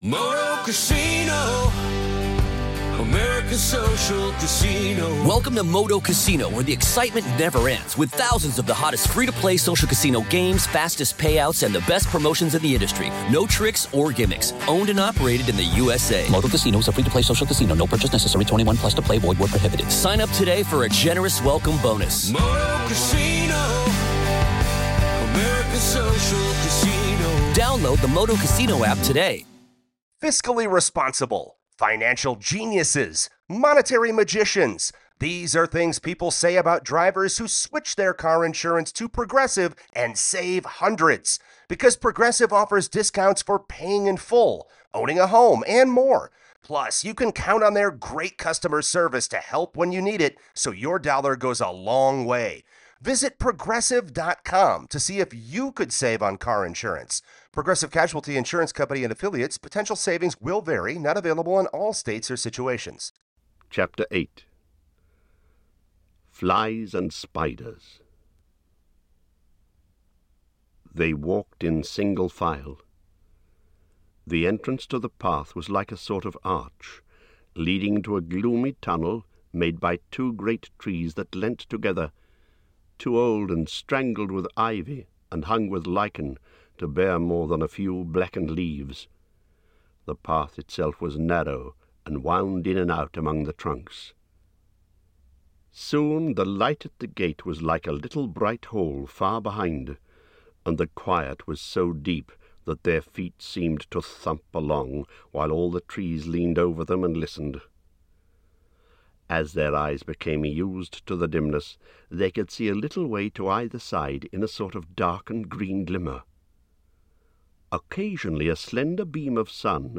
The Hobbit by J. R. R. Tolkien Audiobook, Chapter 08 of 19